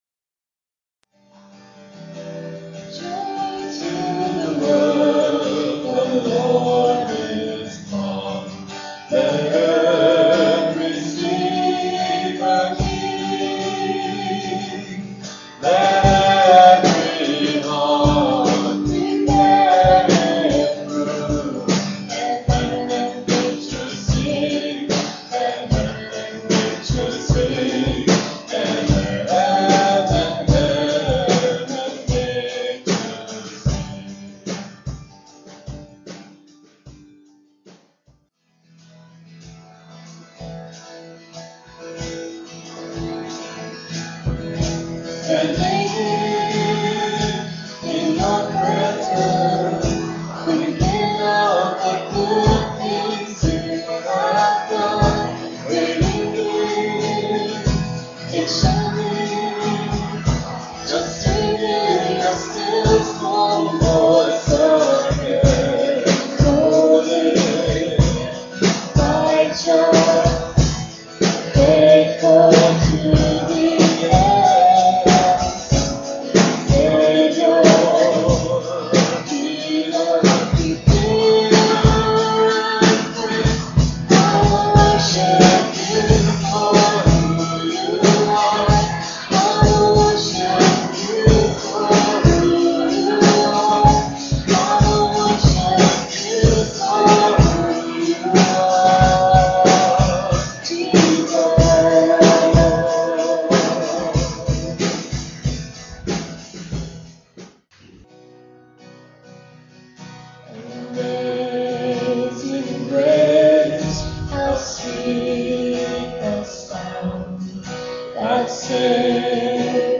PLAY Christmas Stories: Story Without a Beginning, Dec. 11, 2011 Scripture: John 1:1-11. Message